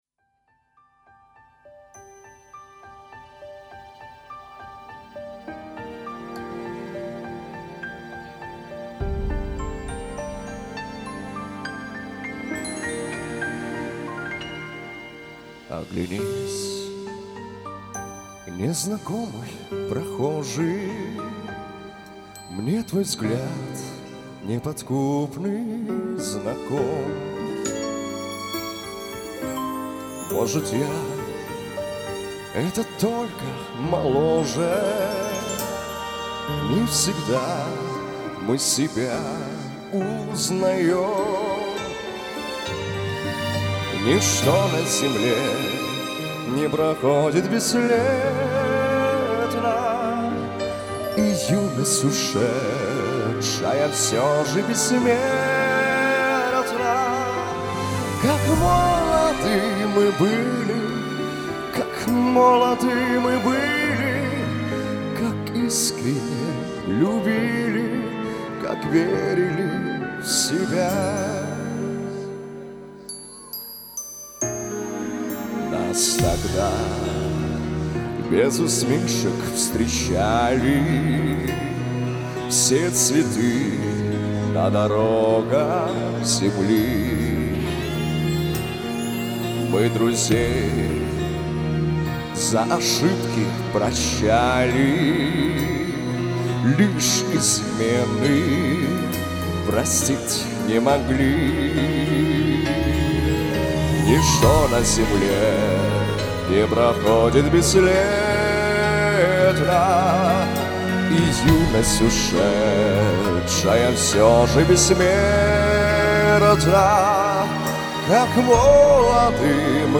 Комментарий инициатора: ЖИВАЯ ЗАПИСЬ!!!! LIVE!!!